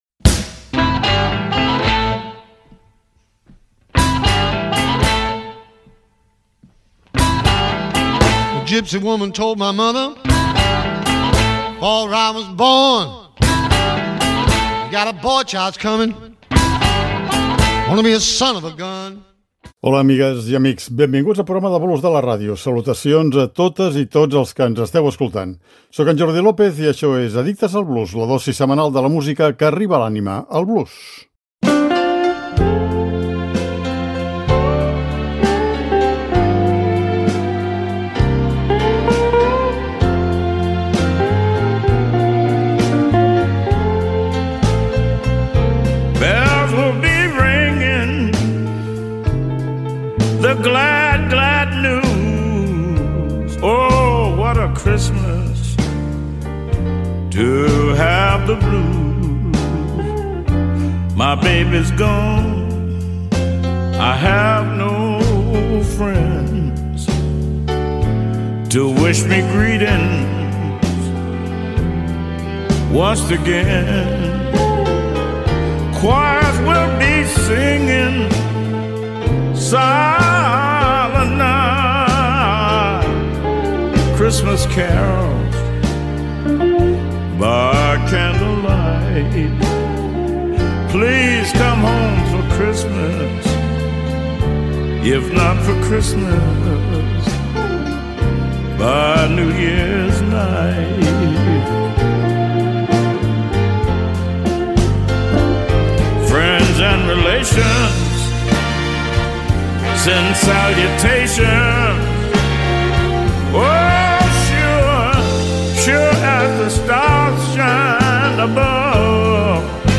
I per això no ens podem estar de dedicar un programa de l’“Addictes”, amb nadales a ritme de blues, perquè el blues arriba a l’anima però també arriba al cor.